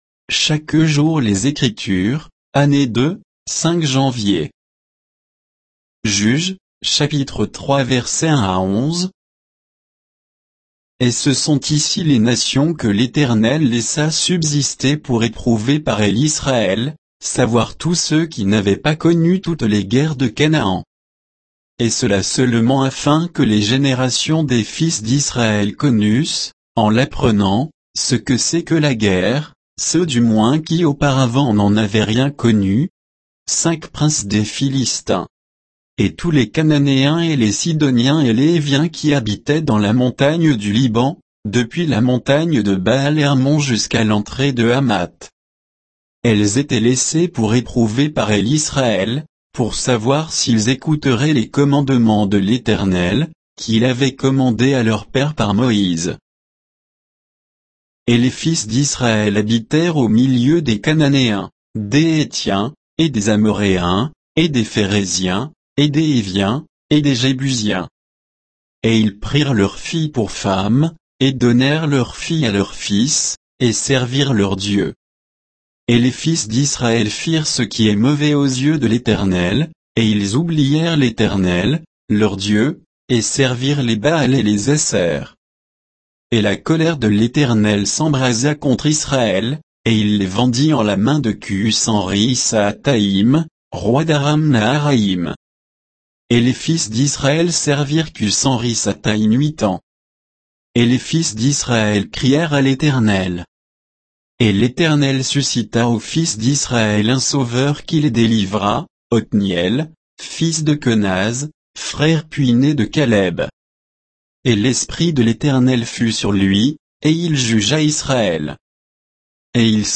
Méditation quoditienne de Chaque jour les Écritures sur Juges 3